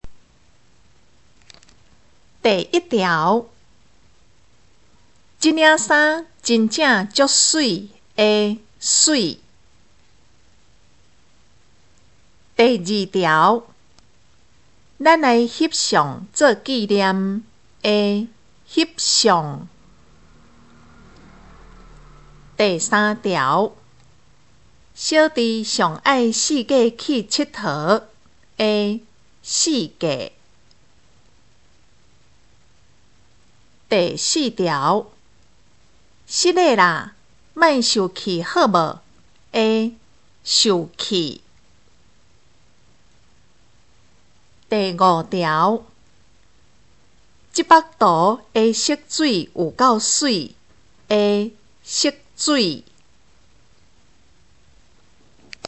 【國中閩南語2】每課評量(4)聽力測驗mp3